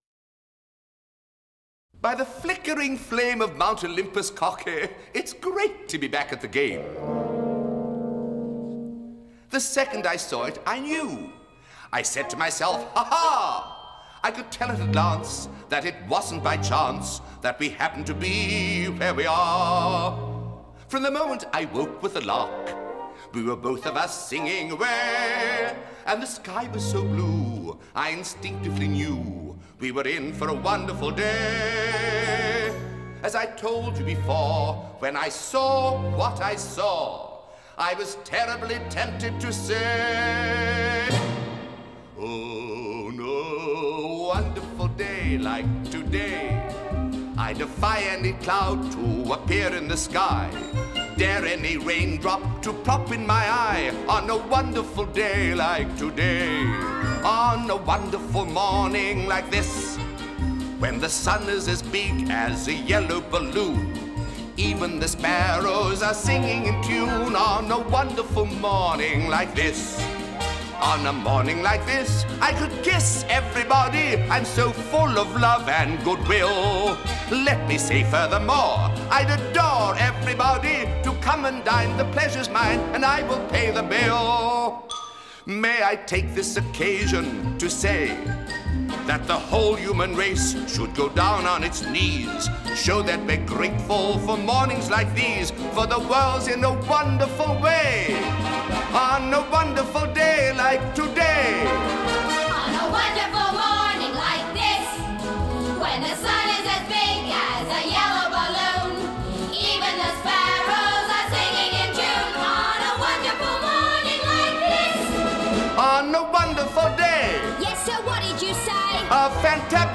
(Original Broadway Cast Recording)